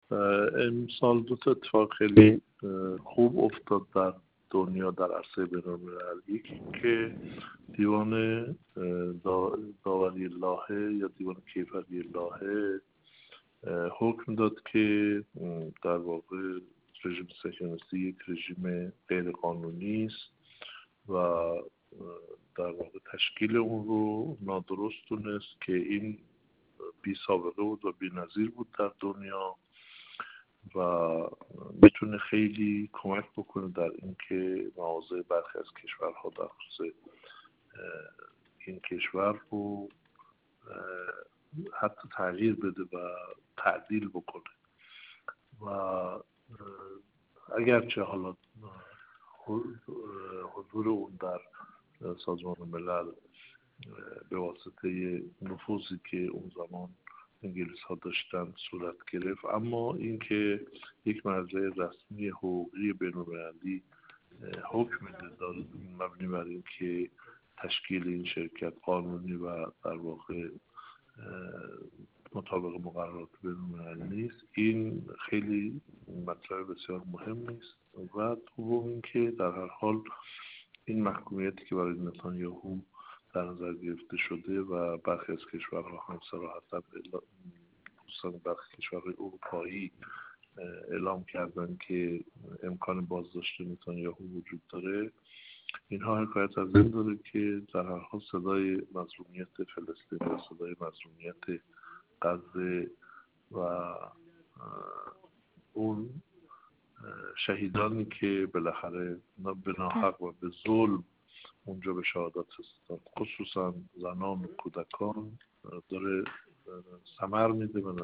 محمدعلی اسفنانی، حقوقدان و سخنگوی سابق کمیسیون قضایی و حقوقی مجلس
گفت‌وگو